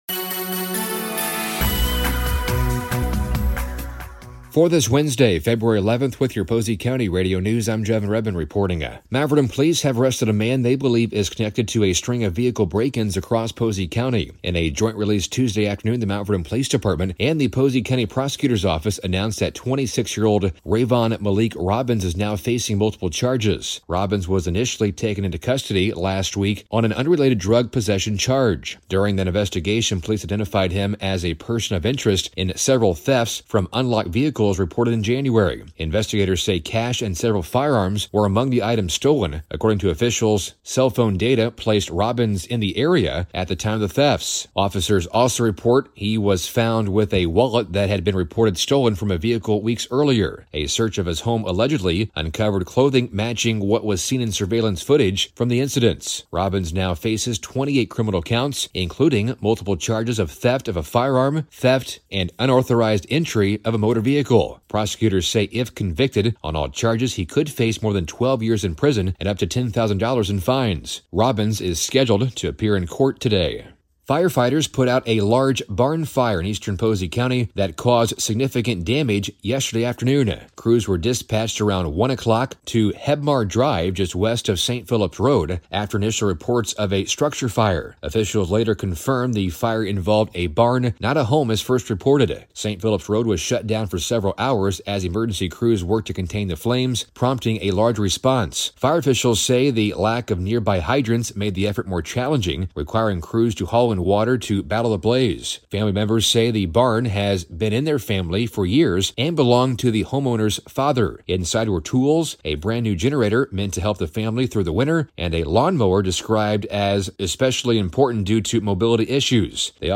Posey County Radio News and Sports